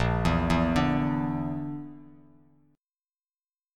A#Mb5 chord